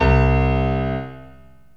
PIANO 0010.wav